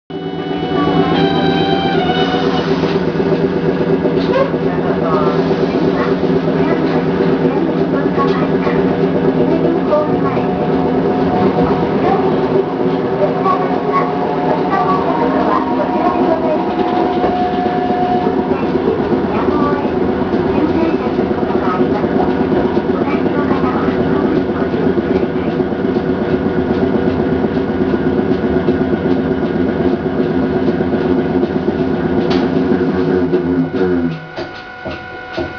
・50形走行音
【城南線】道後公園→南町@（36秒：195KB）…73号にて
一応前・中・後期で分けたのですが基本的に音は同じで、全て吊り掛け式。個人的には、数ある路面電車の中でもかなり派手な音を出す部類に感じました。